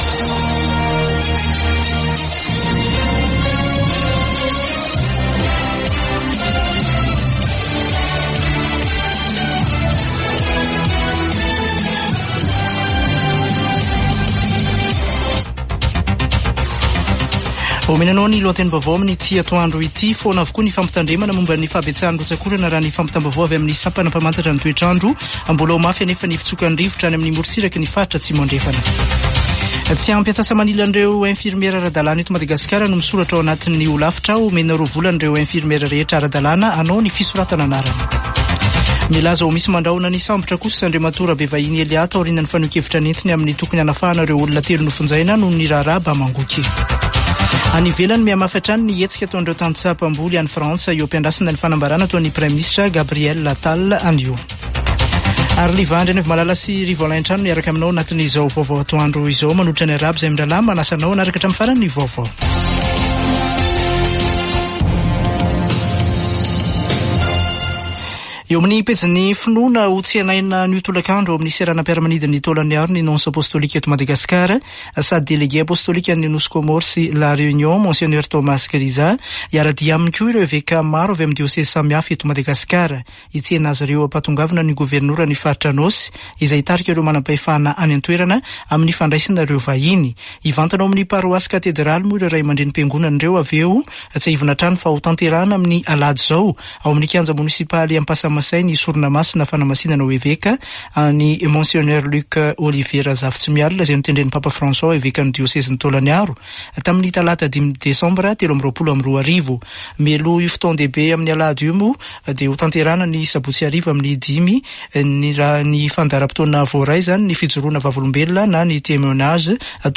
[Vaovao antoandro] Zoma 26 janoary 2024